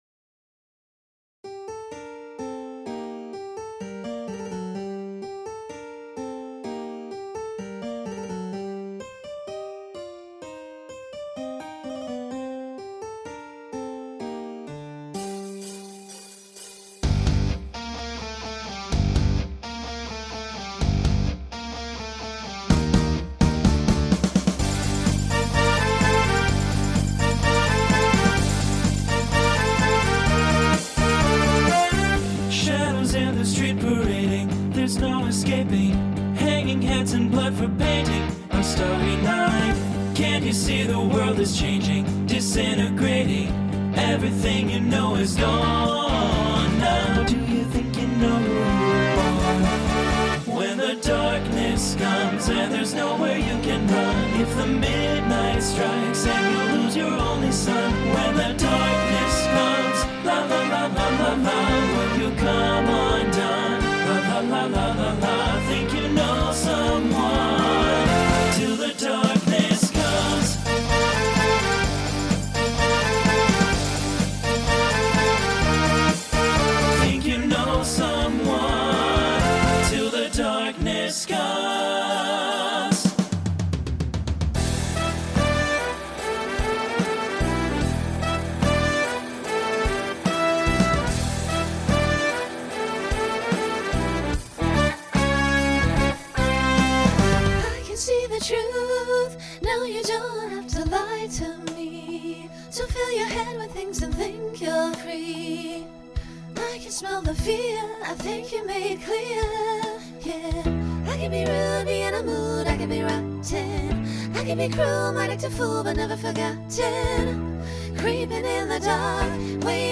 Genre Rock Instrumental combo
Transition Voicing Mixed